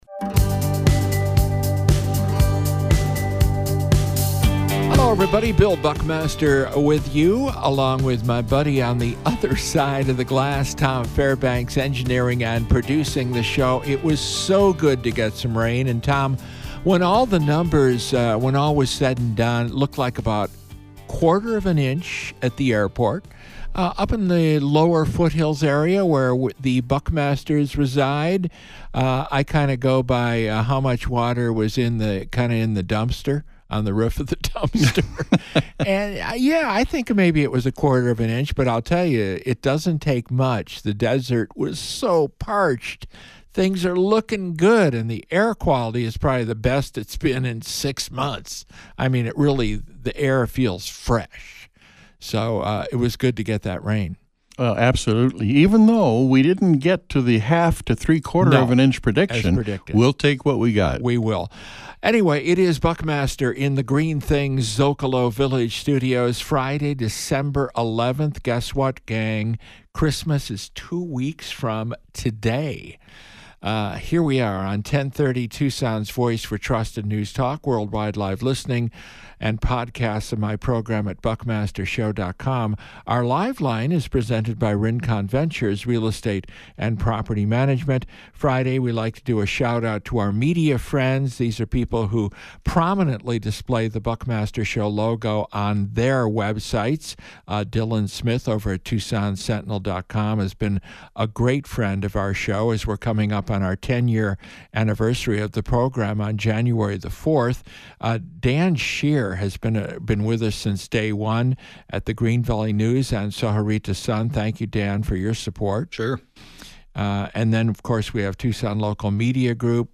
A conversation with Pima County’s new sheriff